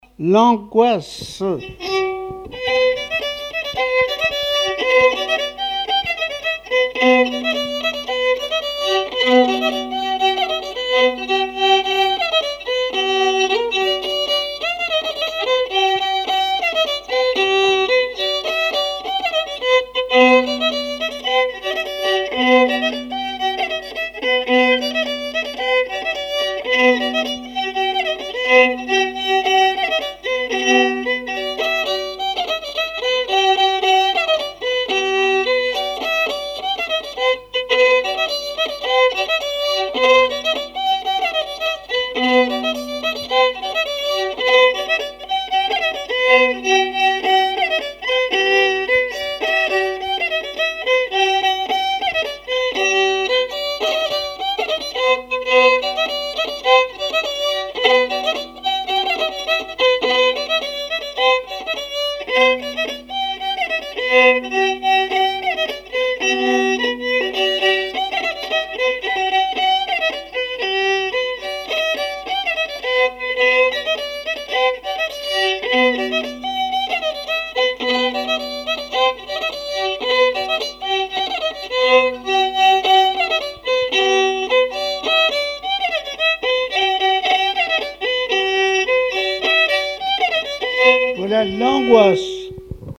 Mémoires et Patrimoines vivants - RaddO est une base de données d'archives iconographiques et sonores.
Chants brefs - A danser
répertoire musical au violon
Pièce musicale inédite